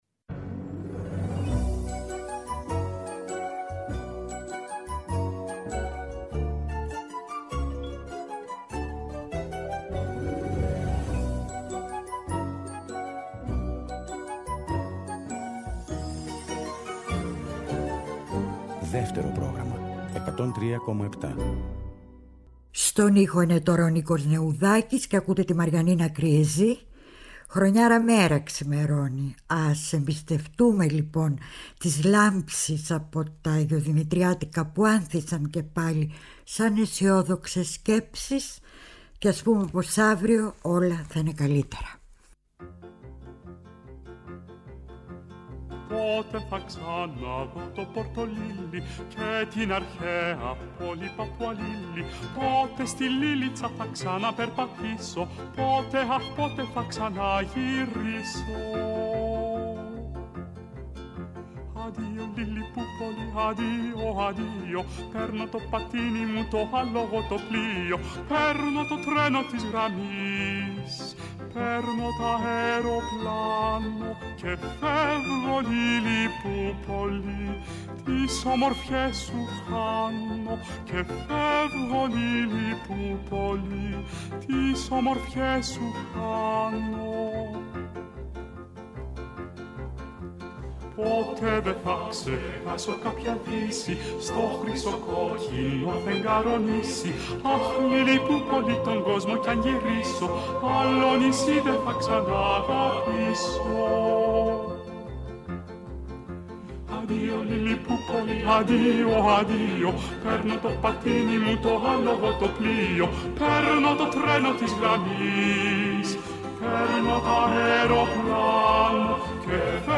διαβάζει αποσπάσματα από συνεντεύξεις, στη μνήμη της Μαριανίνας Κριεζή (1947-2022).